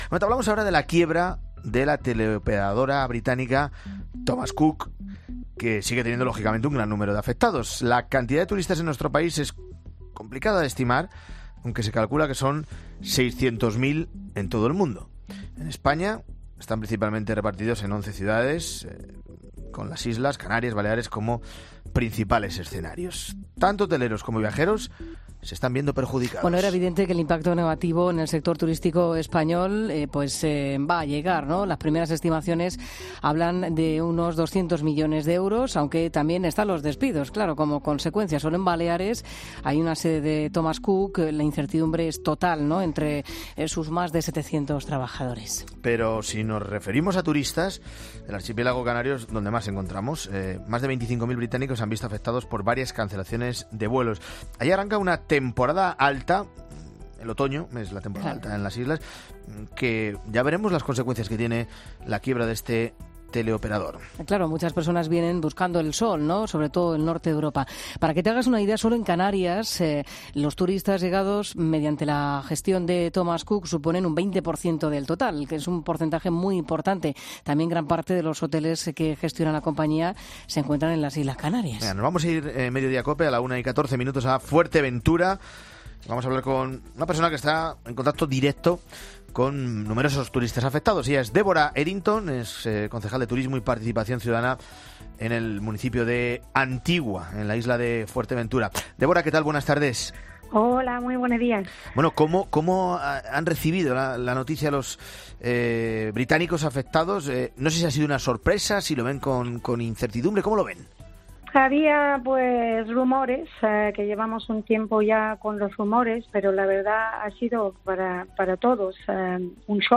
La quiebra de Thomas Cook, vista por una concejal británica en Fuerteventura
Se trata de Deborah Edginton, concejal de Turismo y Participación Ciudadana en el municipio de Antigua, en Fuerteventura. Ella es de origen escocés y reside allí desde hace 23 años.